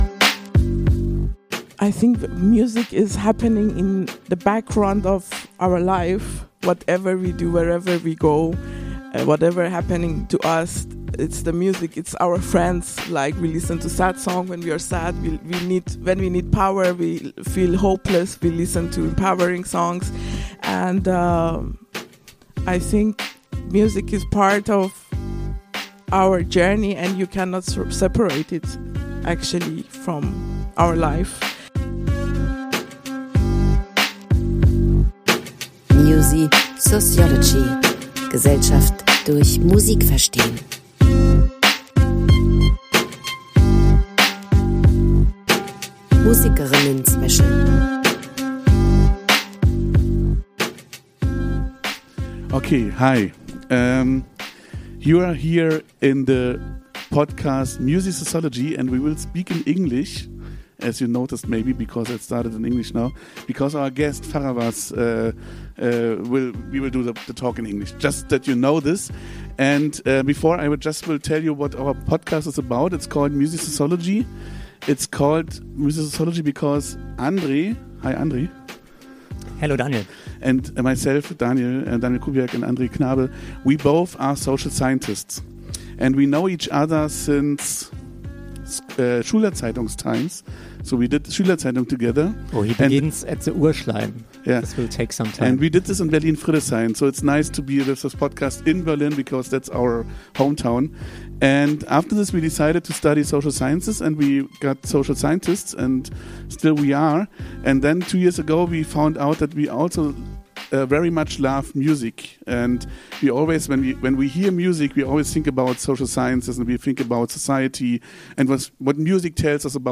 Music is the Background of our Lives - Livepodcast at tazlab 2025